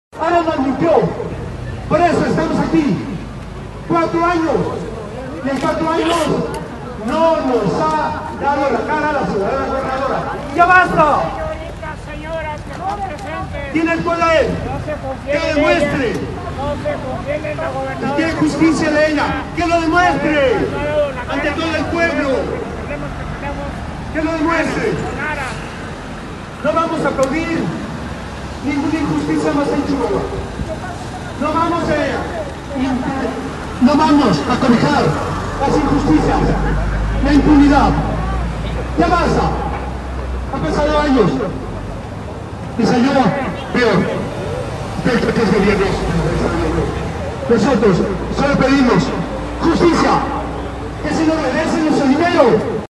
AUDIO: MANIFESTANTES AFECTADOS POR ARAS BUSSINESS GROUP
Chihuahua, Chih.- Un contingente conformado por varias decenas de personas, afectadas por la entidad financiera Aras Business Grouip, se manifestaron esta mañana a las afueras de hotel Sheraton en la capital del estado y en donde la gobernadora María Eugenia Campos, se encuentra encabezando la plenaria ordinaria de la Comisión Nacional de Tribunales de Justicia (CONATRIB).
MANIFESTANTES.mp3